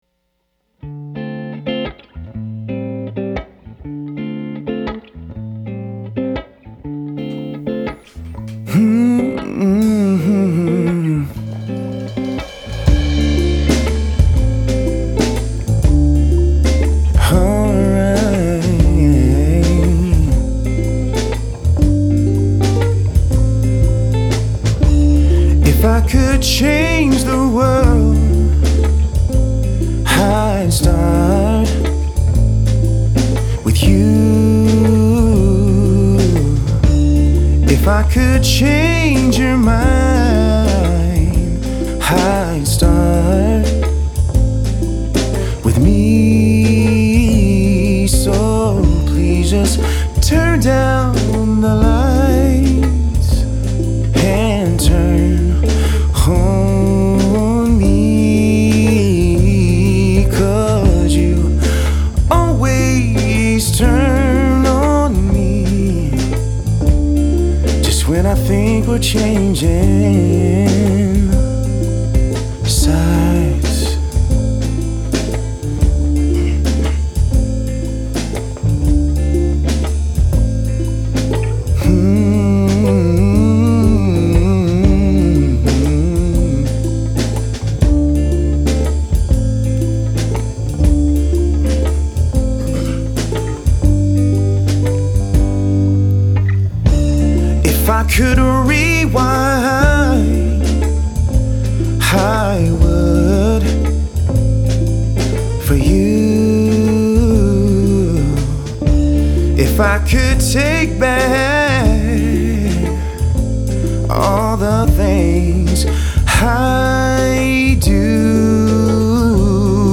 Auf den Leadvocals sind jeweils einmal Urschrei 1176 HW und einmal ein 1176 Plugin.